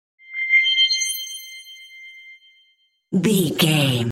Sound Effects
Atonal
funny
magical
mystical